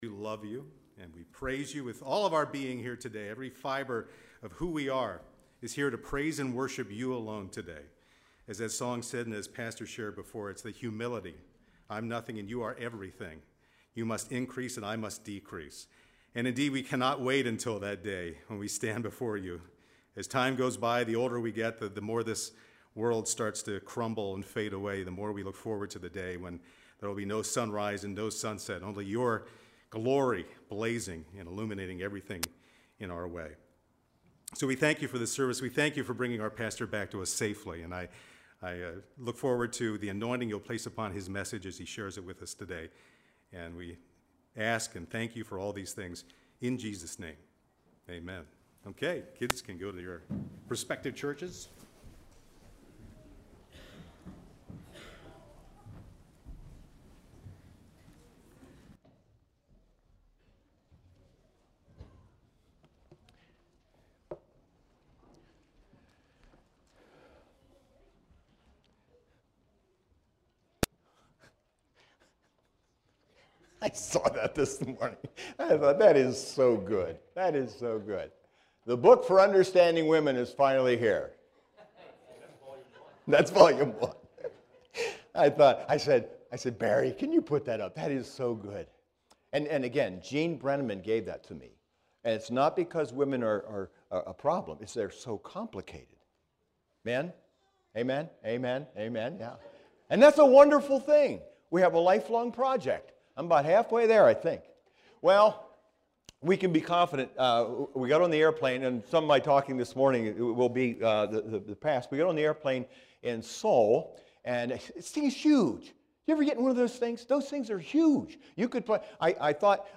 SERMONS - Grace Fellowship Church